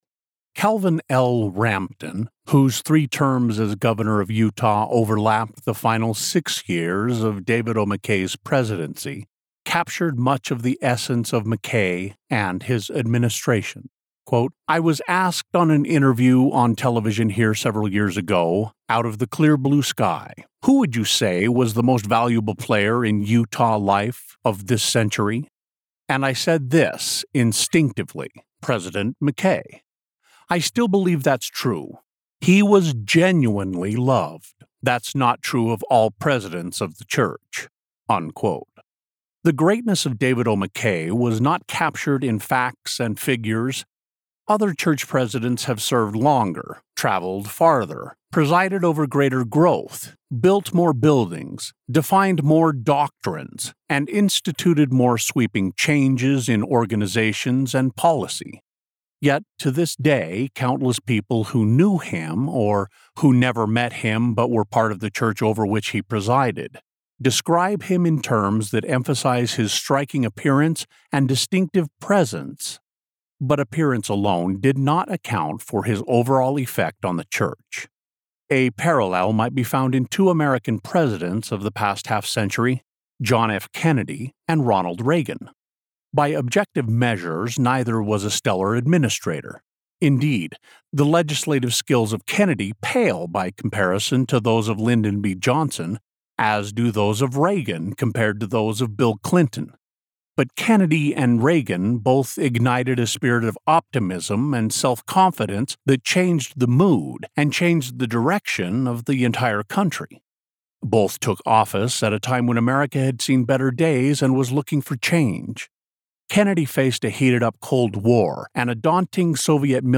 Rich • Robust • Trustworthy
A warm embrace of fatherly wisdom.
Non-Fiction • Biography
General American